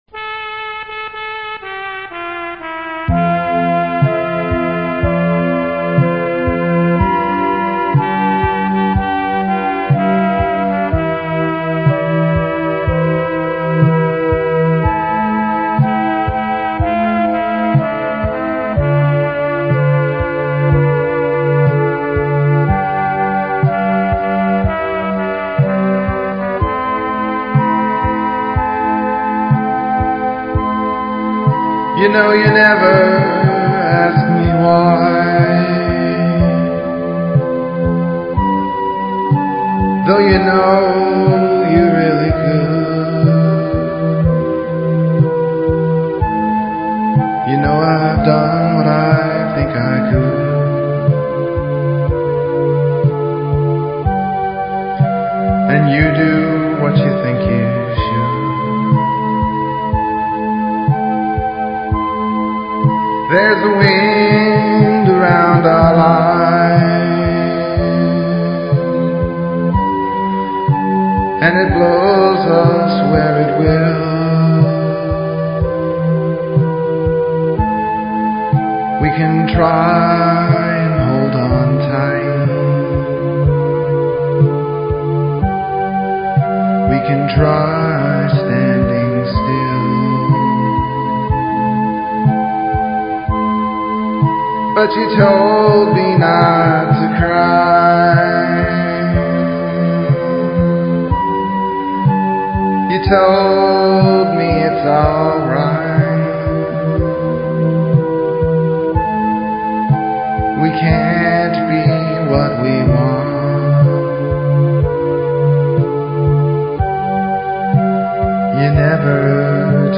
So so sad; he just can’t get along no matter how hard he wonders. Executive action summary: guy’s girlfriend will not explain herself — or him, the sky above etc. • Words & music copyright 1986